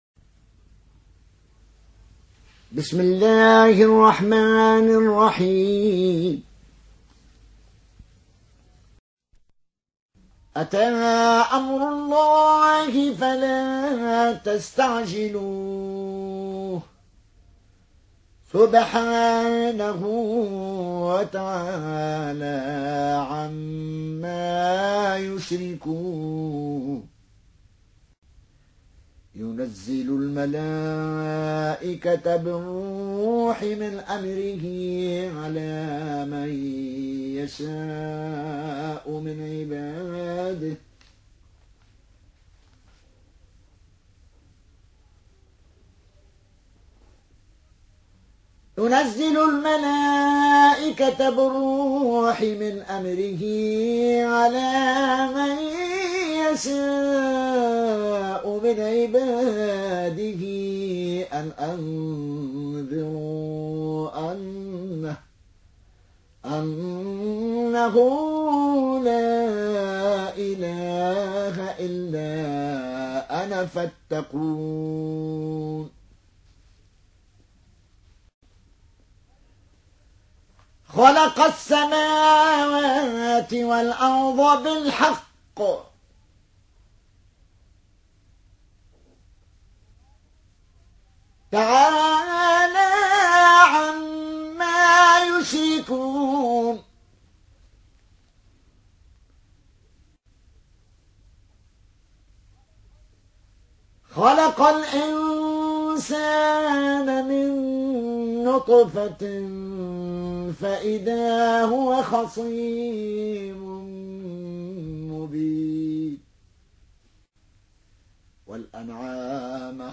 (رواية قالون)